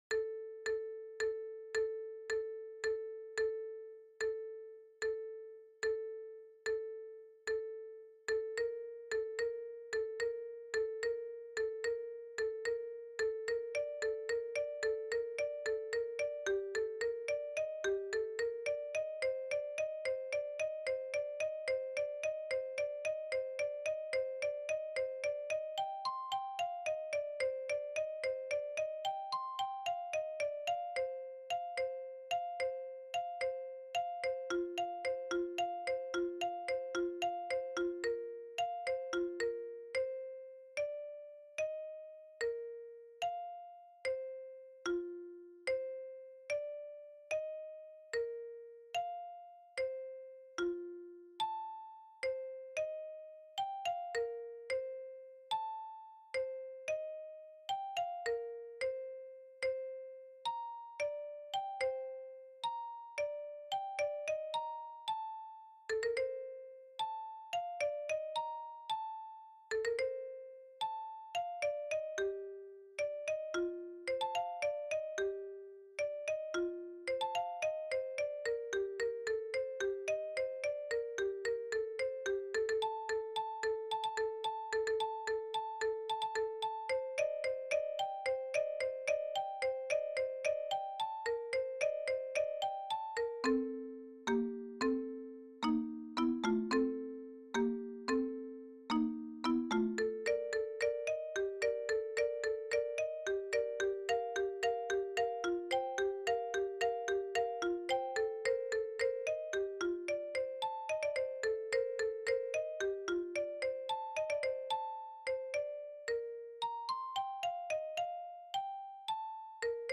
80 bpm            melodic patterns at
110 bpm
PatternStudy2_patterns_110bpm.mp3